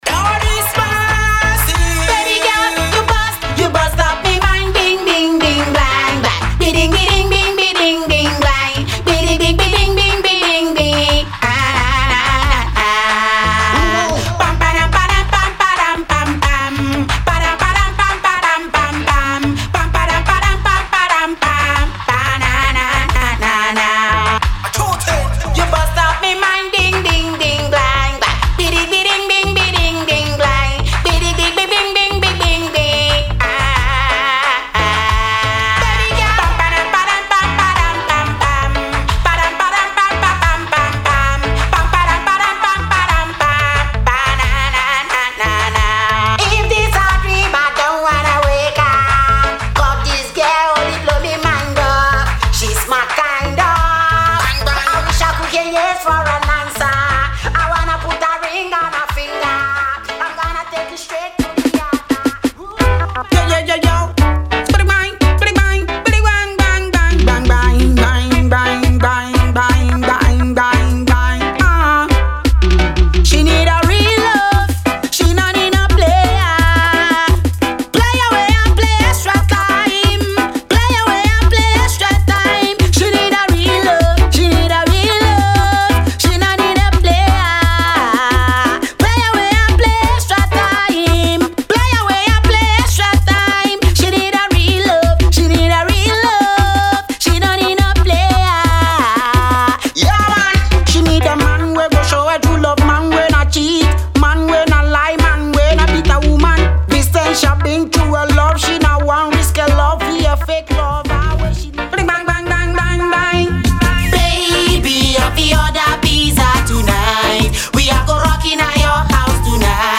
Genre:Dancehall
デモサウンドはコチラ↓
76 Male Vocal Loops
30 Dry Vocal Hits
20 Wet Vocal Hits